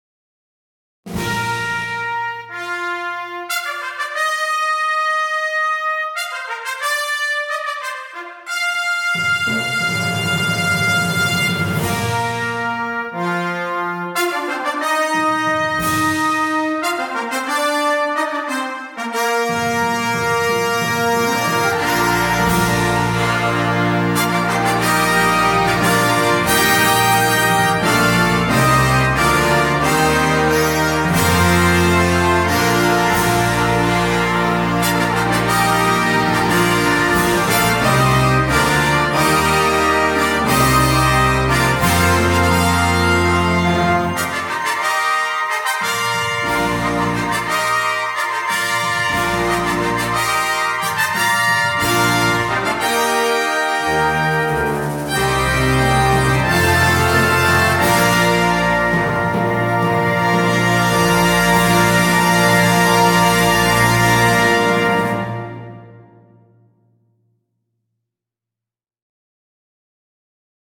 CategoryBand Fanfare
InstrumentationPiccolo
Flutes 1-2
Oboes 1-2
Bb Clarinets 1-2-3
Eb Alto Saxophones 1-2
Horns in F 1-2
Bb Trumpets 1-2-3
Tenor Trombones 1-2
Euphonium
Tuba
Timpani
Tubular Bells